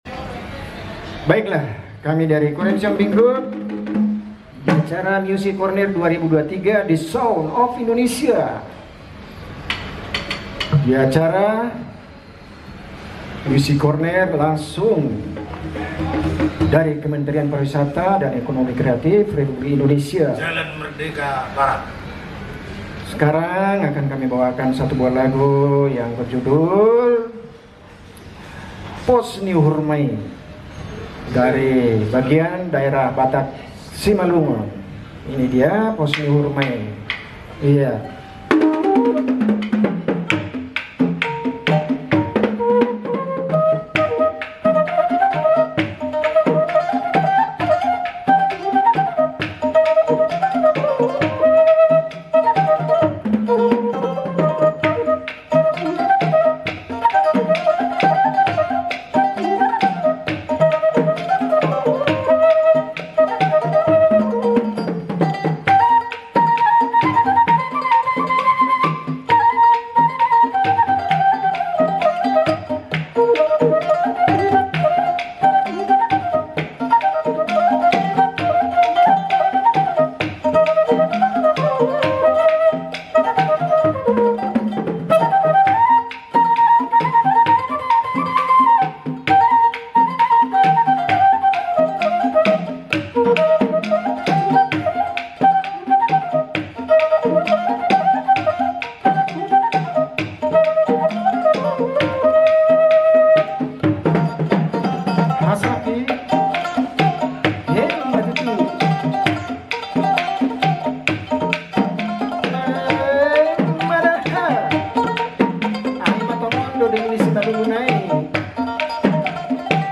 Live Kementerian Pariwisata dan Ekonomi kreatif RI🇮🇩 Music CORNER 2023 The Sound Of Indonesia